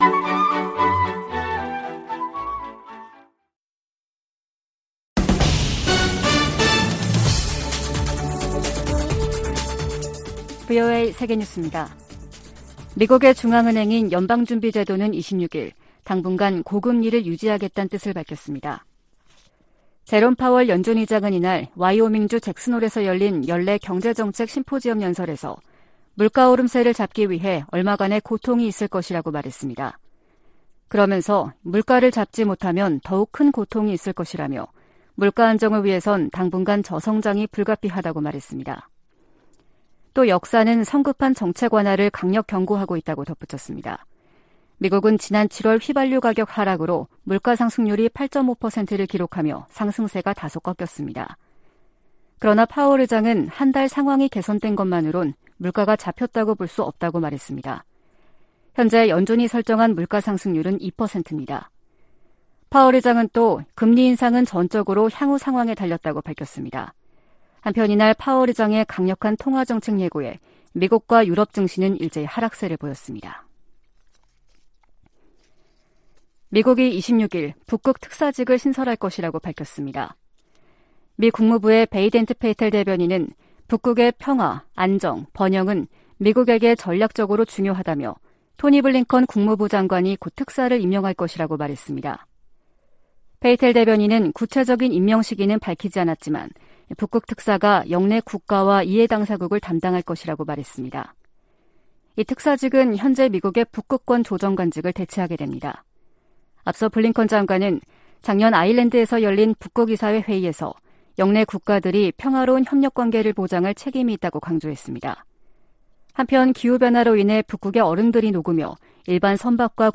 VOA 한국어 아침 뉴스 프로그램 '워싱턴 뉴스 광장' 2022년 8월 27일 방송입니다. 미 국무부 차관보와 한국 외교부 차관보가 서울에서 회담하고 북한의 도발 중단과 대화 복귀를 위한 공조를 강화하기로 했습니다. 미 국무부는 반복되는 러시아와 중국 폭격기의 한국 방공식별구역 진입을 역내 안보에 대한 도전으로 규정했습니다. 미국과 한국 정부가 중국 내 탈북 난민 상황을 거듭 우려하며 중국 정부에 난민 보호에 관한 국제의무 이행을 촉구했습니다.